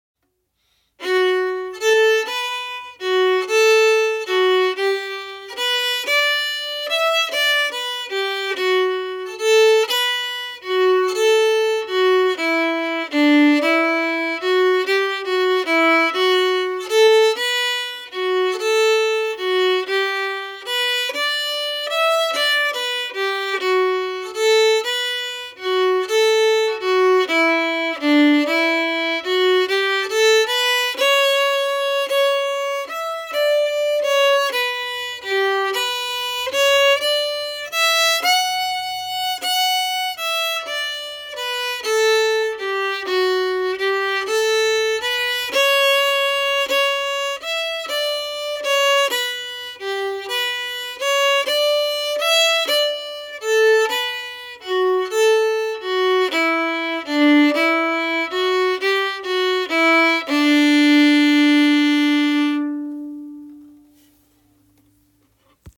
The Snowy Path no slurs slow (MP3)Download
the-snowy-path-no-slurs-slow.mp3